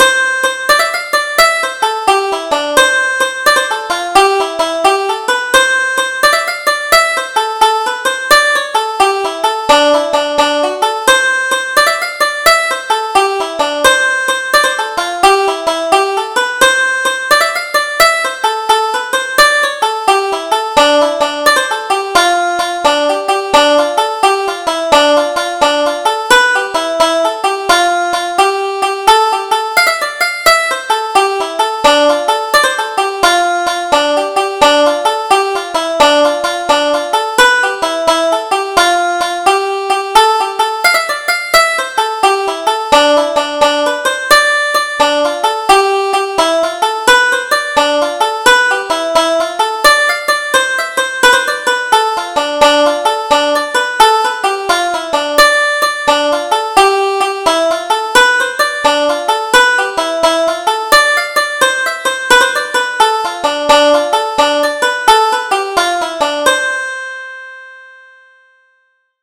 Double Jig: The Belles of Liscarroll